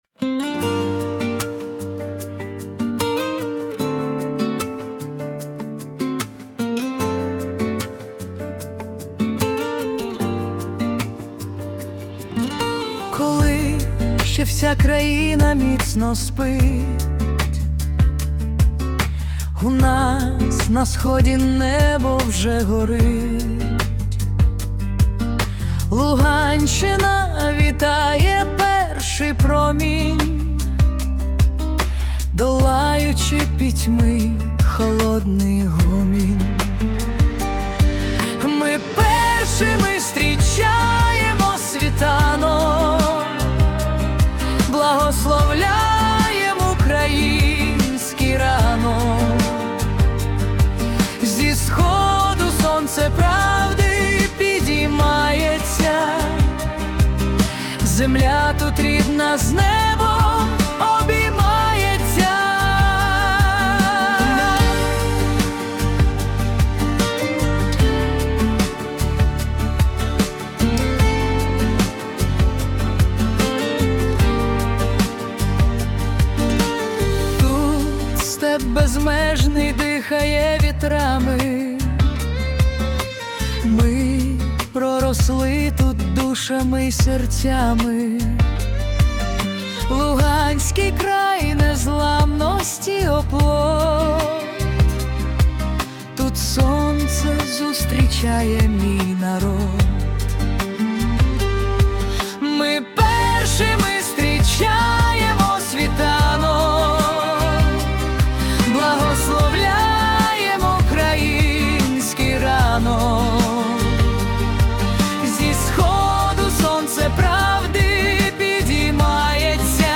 🎵 Жанр: Акустична балада